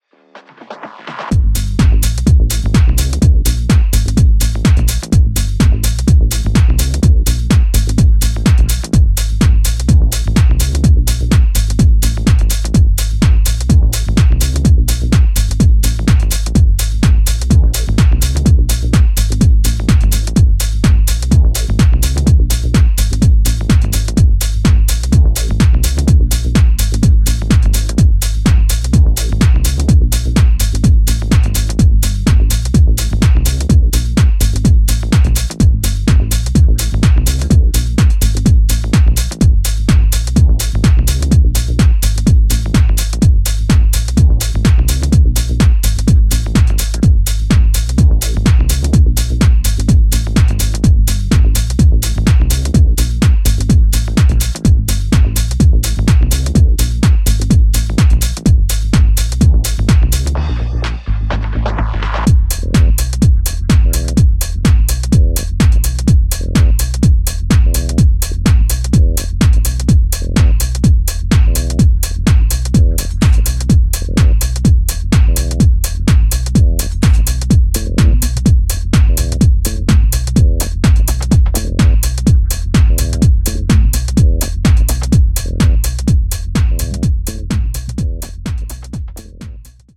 ジャンル(スタイル) TECH HOUSE / HOUSE